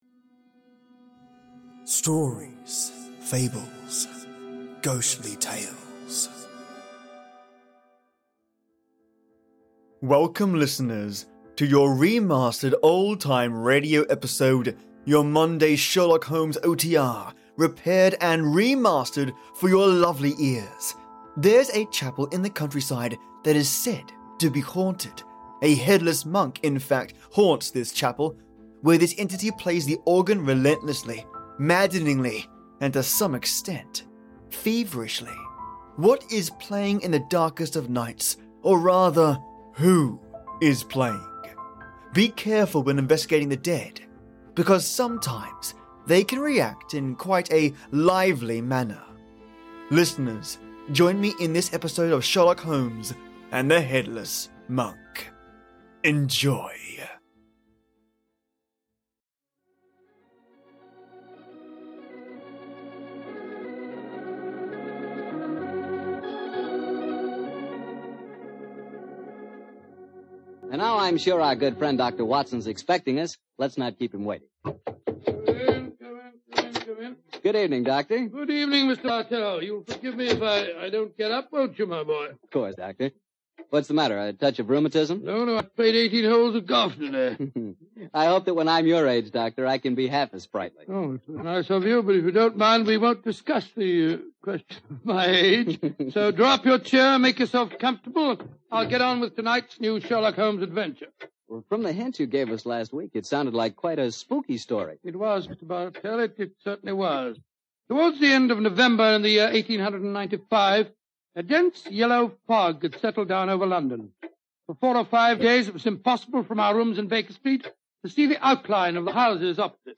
Sherlock Holmes and The Headless Monk | Repaired Vintage Radio
Your Monday Sherlock Holmes OTR, repaired and mastered for your lovely ears. There’s a chapel in the country side that is said to be haunted, a headless monk in fact, haunts this chapel, where this entity plays the organ relentlessly, maddeningly, and to some extent…feverishly.